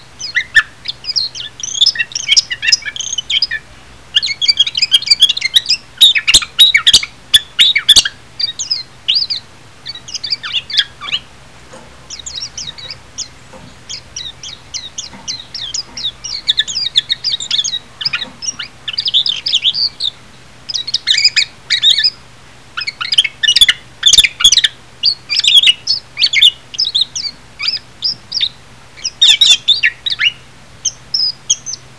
仲間を呼ぶメジロの鳴き声→クリック
mejiro2-nakama2.wav